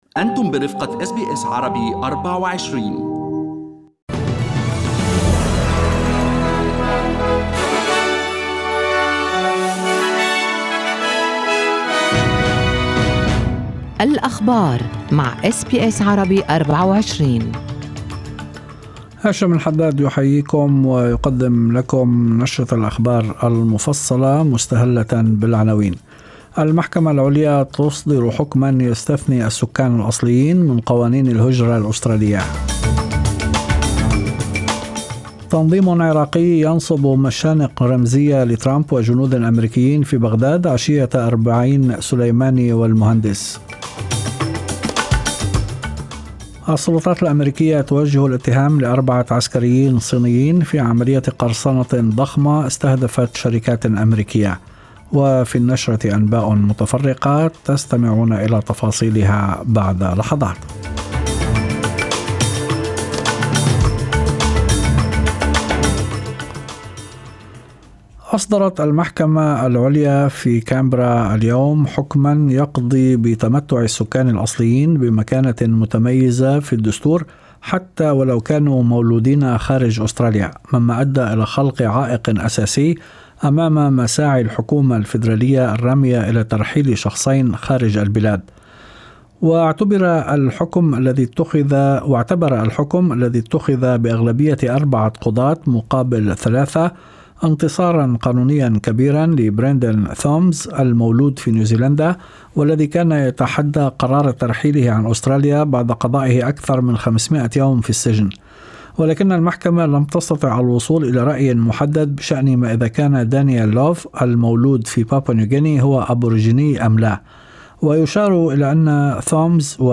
نشرة أخبار المساء 11/02/2020
Arabic News Bulletin Source: SBS Arabic24